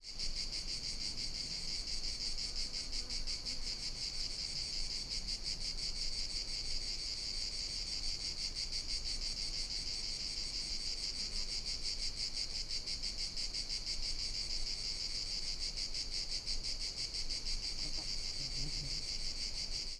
cicadas_ambiX.wav